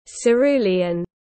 Cerulean /səˈruː.li.ən/